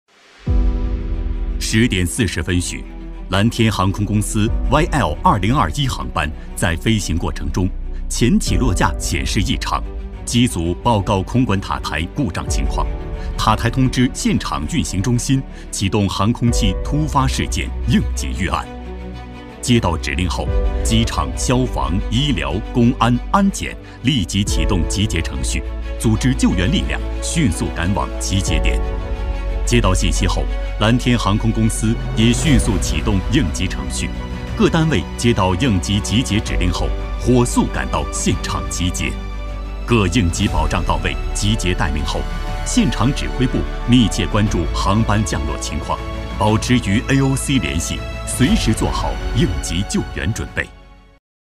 男14-应急救援（紧迫、正式）
男14-透亮故事感 大气浑厚
男14-应急救援（紧迫、正式）.mp3